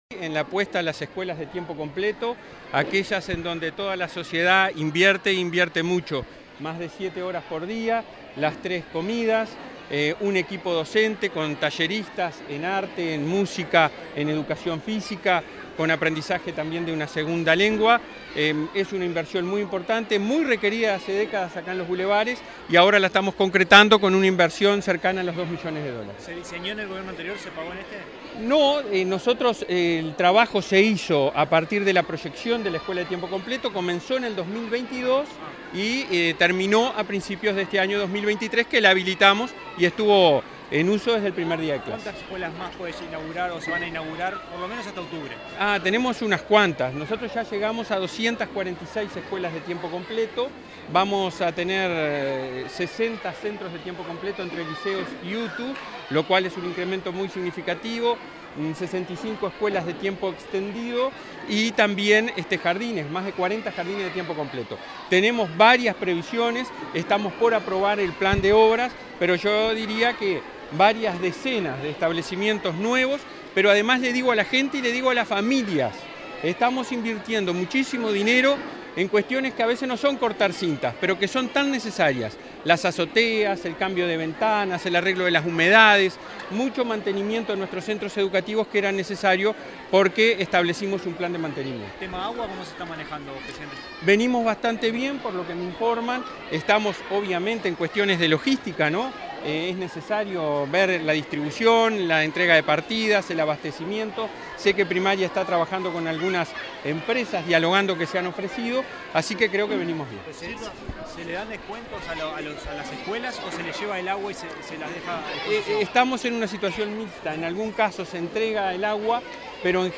Declaraciones del presidente de ANEP, Robert Silva
Declaraciones del presidente de ANEP, Robert Silva 23/05/2023 Compartir Facebook X Copiar enlace WhatsApp LinkedIn Tras la inauguración de la escuela n.° 407, de tiempo completo, este 23 de mayo, el presidente del Consejo Directivo Central (Codicen) de la Administración Nacional de Educación Pública (ANEP), Robert Silva, realizó declaraciones a la prensa.